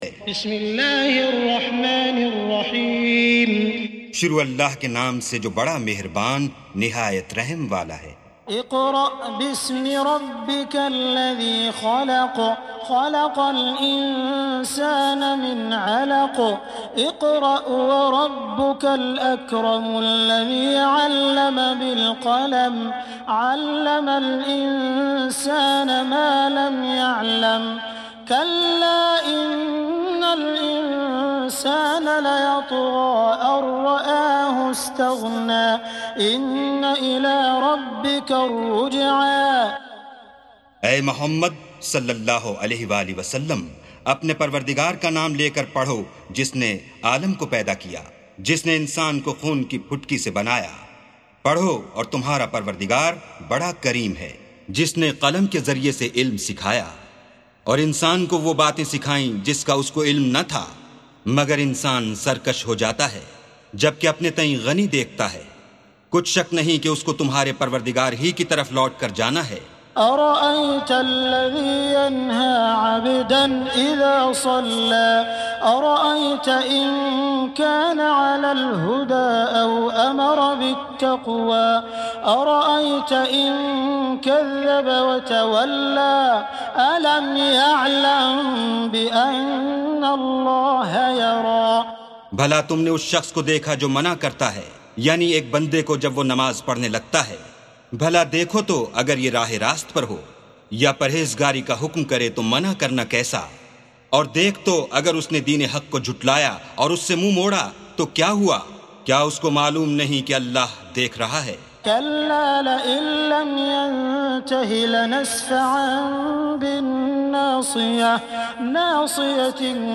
سُورَةُ العَلَقِ بصوت الشيخ السديس والشريم مترجم إلى الاردو